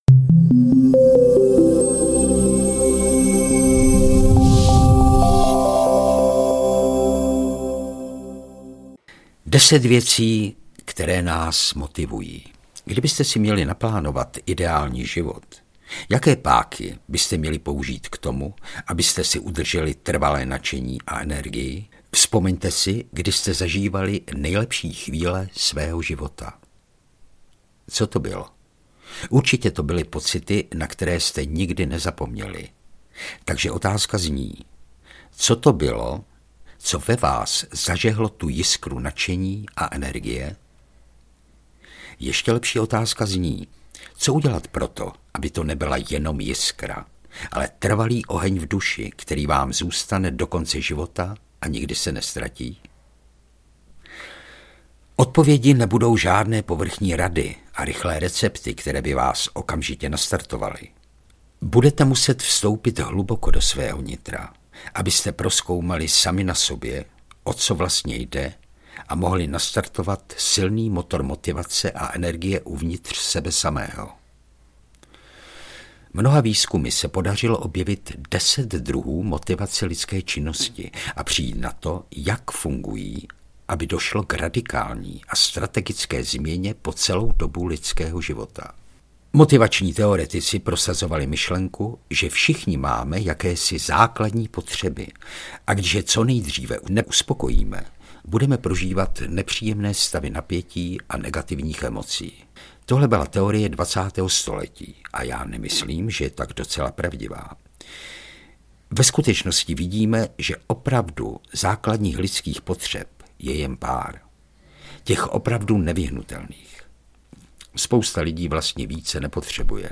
Největší zdroje a zloději vaší energie audiokniha
Ukázka z knihy